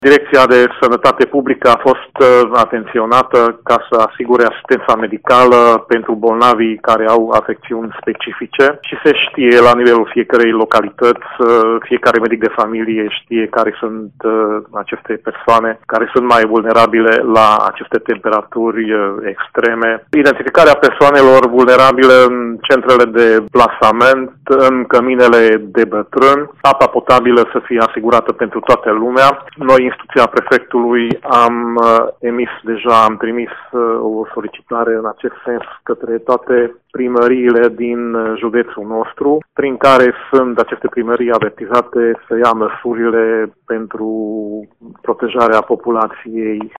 De asemenea, primăriile vor trebui să acopere necesarul de apă potabile prin cisterne, a explicat subprefectul județului Mureș, Nagy Zsigmond.